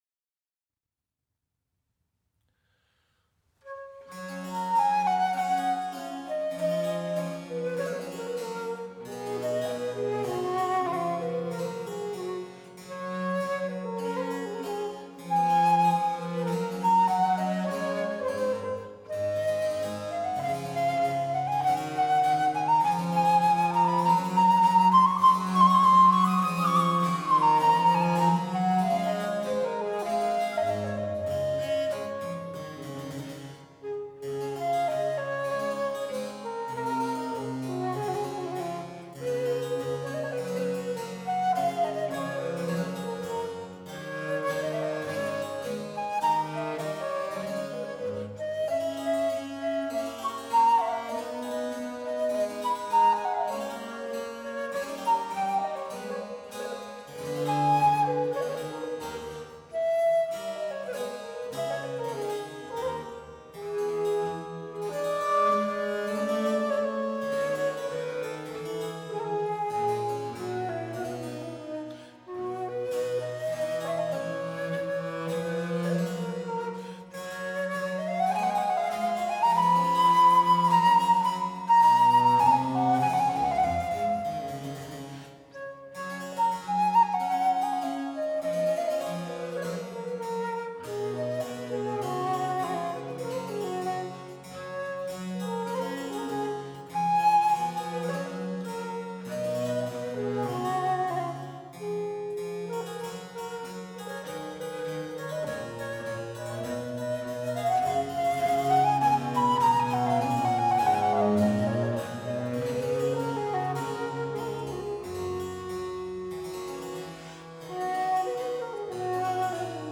Sonate G-Dur für Traversflöte und Basso Continio – Andante (CD »Friedrich II. in Potsdam.